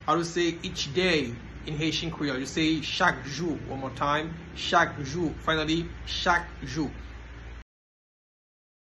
Listen to and watch “Chak jou” pronunciation in Haitian Creole by a native Haitian  in the video below:
Each-day-in-Haitian-Creole-Chak-jou-pronunciation-by-a-Haitian-teacher.mp3